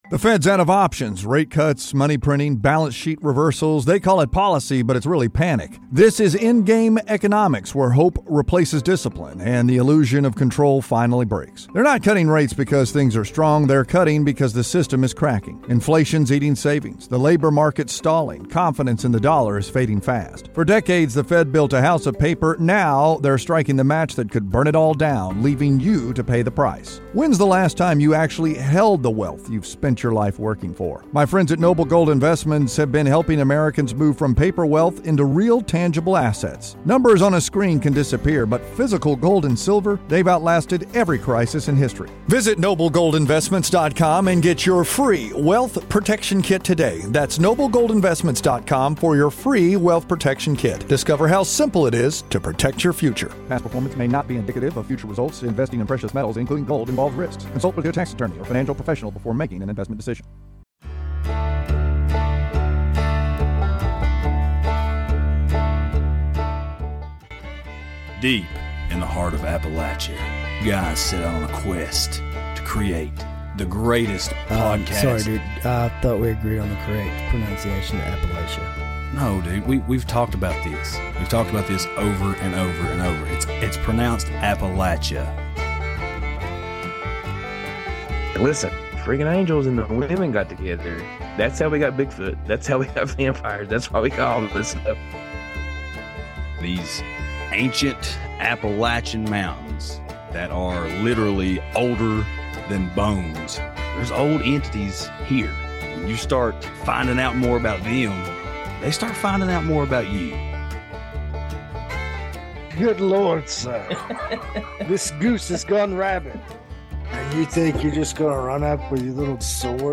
The guys chat it up about CERN located right outside Geneva, Switzerland. CERN is a an organization of scientists that delve into particle and Quantum physics in an attempt to explore and define the universe and how it was made.